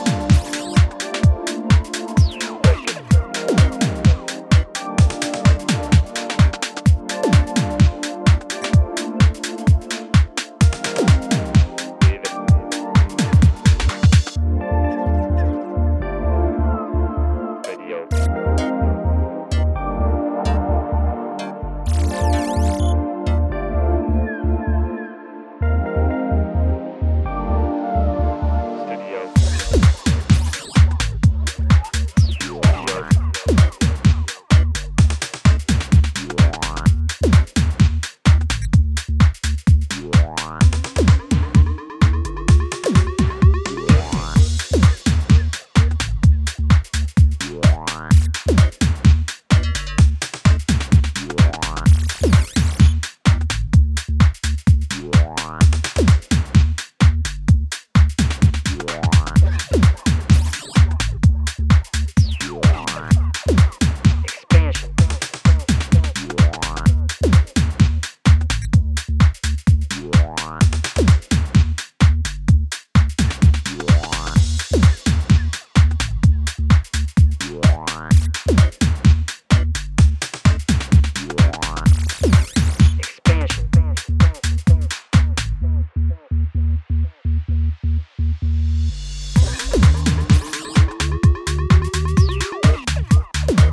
Techy, gritty and soft in all the right places.